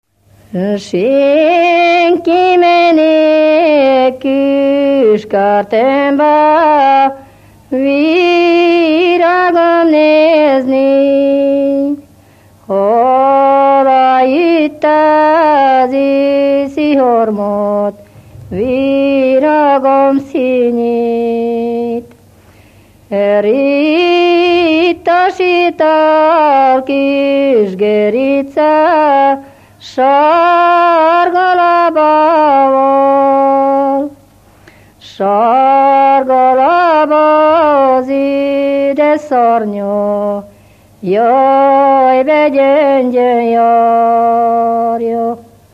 Moldva és Bukovina - Moldva - Klézse
ének
Stílus: 7. Régies kisambitusú dallamok